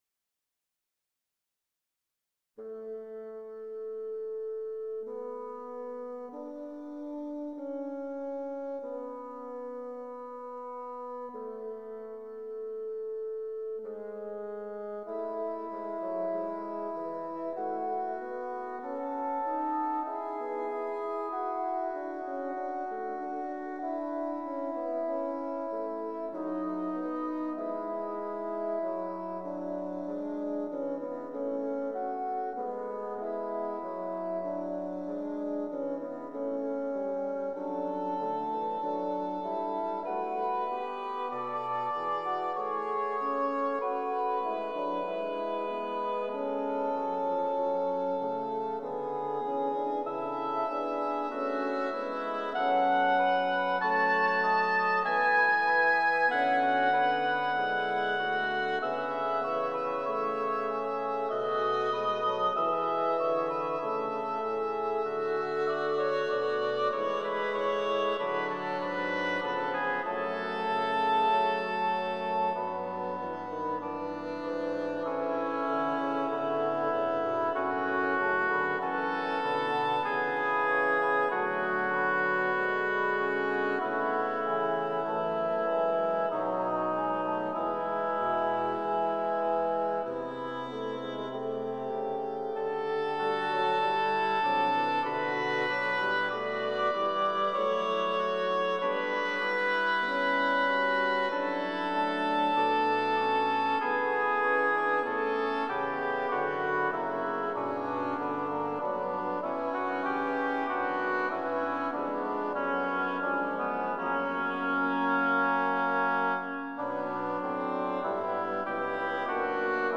Double Reeds   2:11 "Oui, ton amour est un lieu sûr" (Inpired on Bach's WTC II Fuga IX)
GP-073-DblRds.mp3